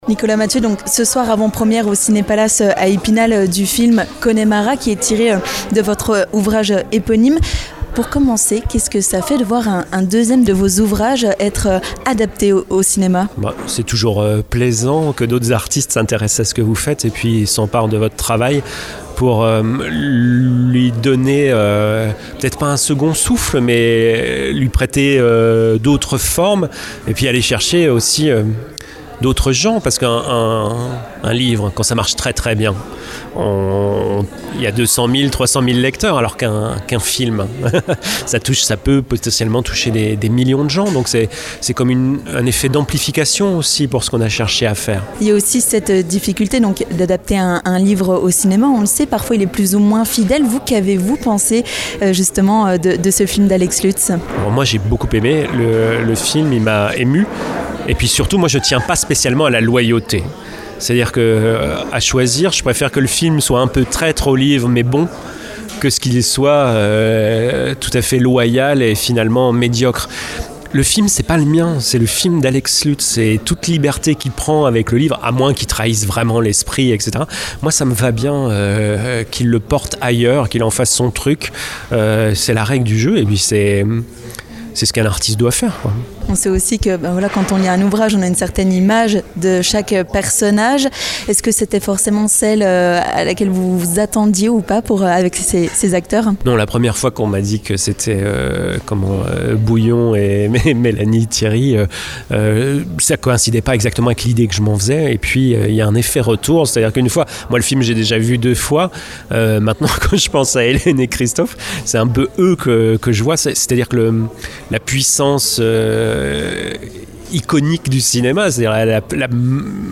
Ce mardi soir, Nicolas Mathieu était présent aux Cinés Palace à Epinal pour l'avant-première du film Connemara, tiré de son roman éponyme.